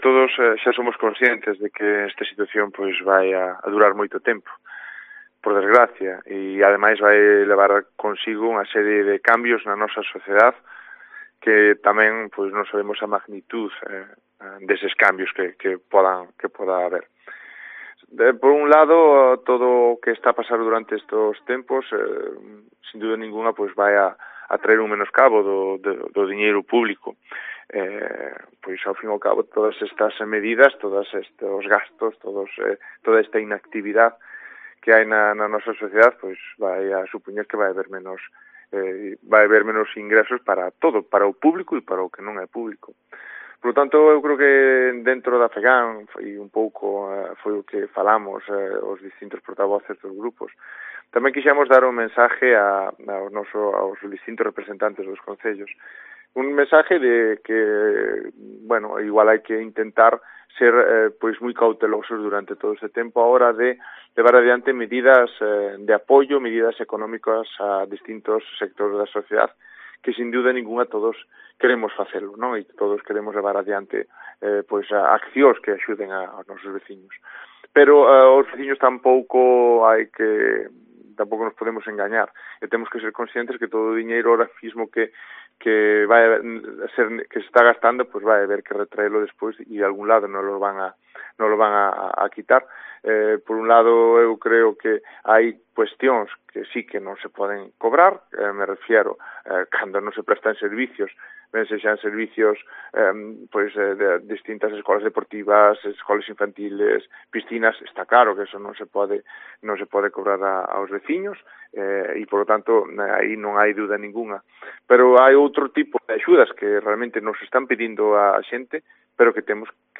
Declaraciones de ALFONSO VILLARES, vicepresidente de la Federación Galega de Municipios e Provincias (FEGAMP)